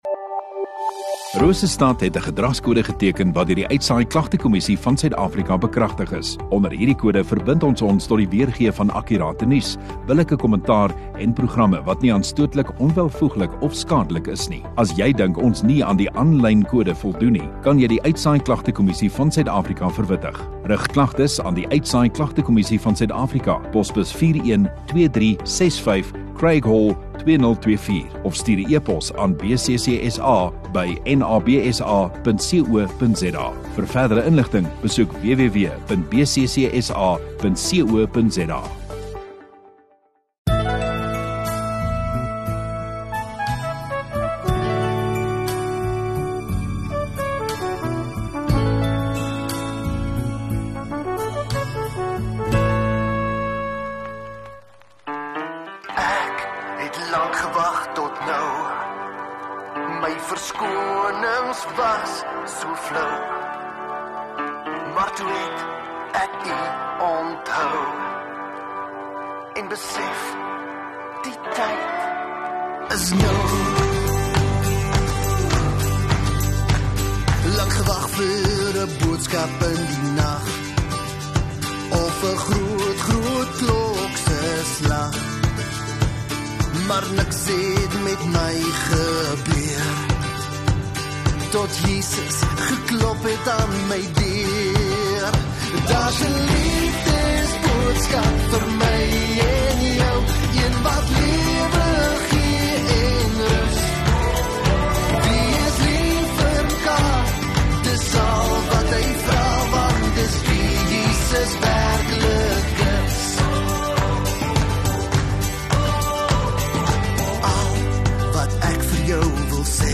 27 Aug Dinsdag Oggenddiens